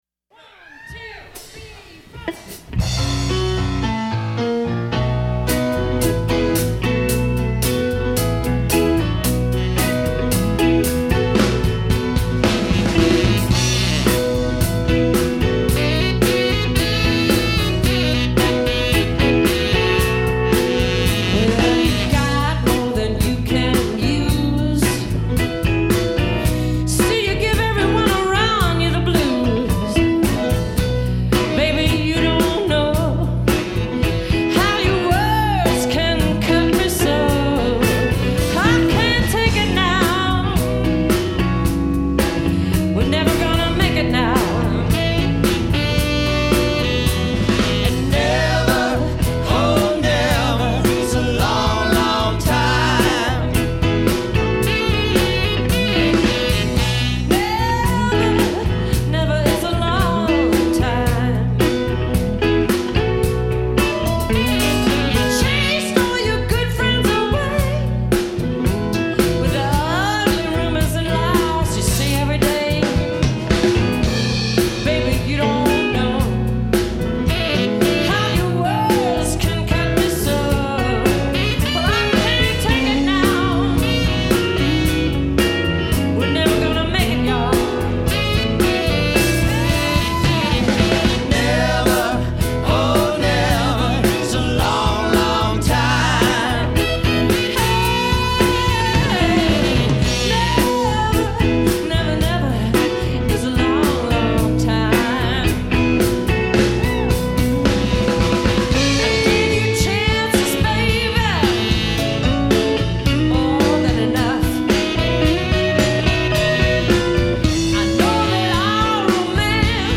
Vocal / Guitar
Guitar
Piano /Backup Vocal
bass
drums
at the Montgomery Co. Fairgrounds